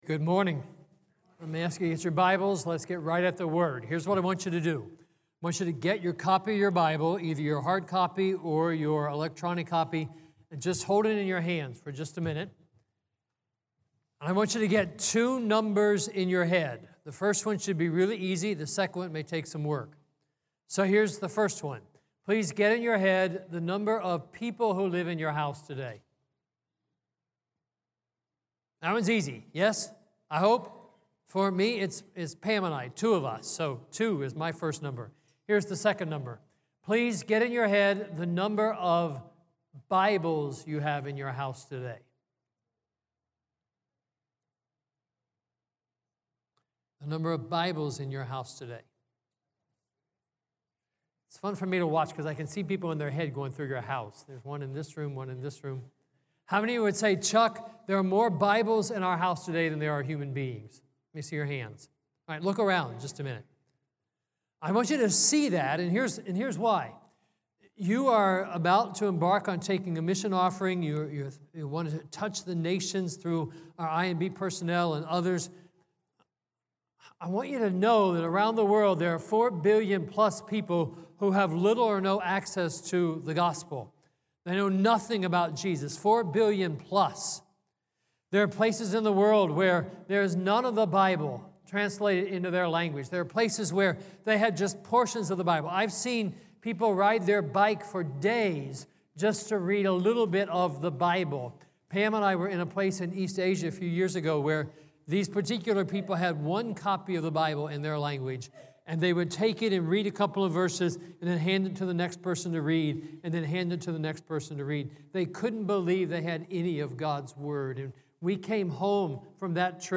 Messages from guest speakers at our weekend services.